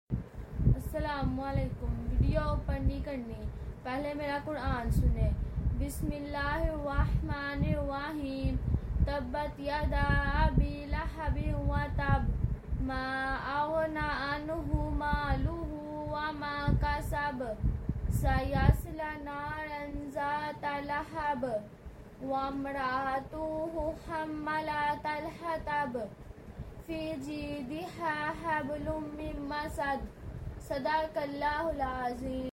قرآن کی تلاوت sound effects free download